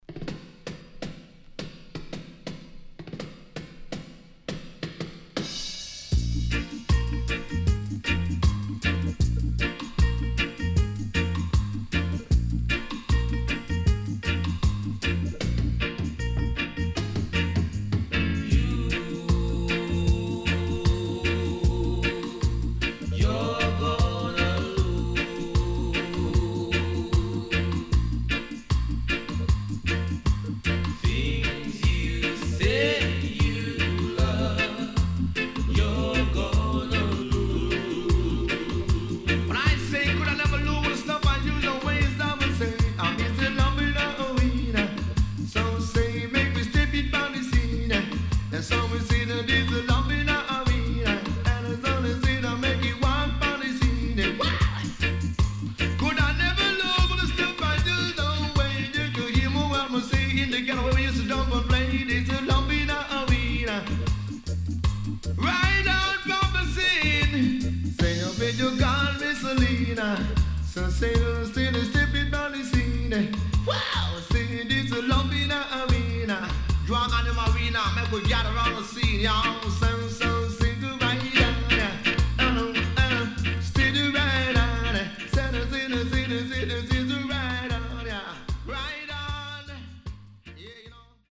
往年の名曲の数々にTalk Overした傑作盤
SIDE B:少しチリノイズ入りますが良好です。